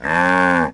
moo1.wav